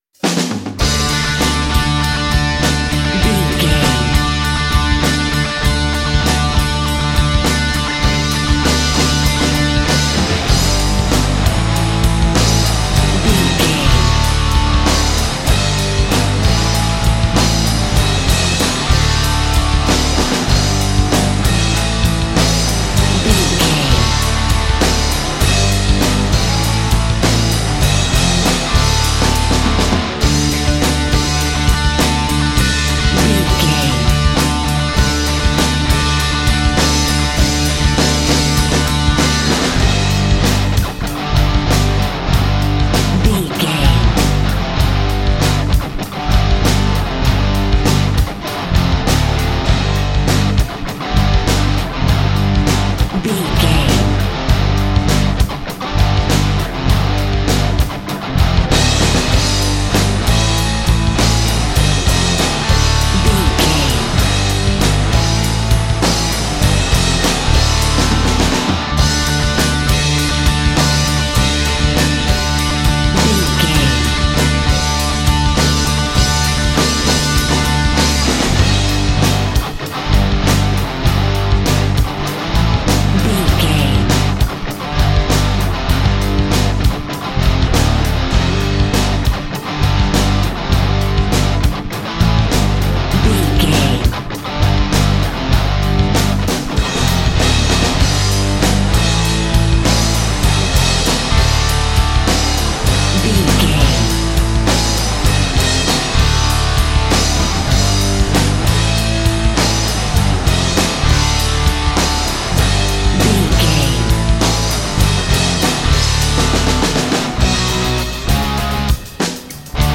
Aeolian/Minor
electric guitar
bass guitar
drums
hard rock
lead guitar
aggressive
energetic
intense
powerful
nu metal
alternative metal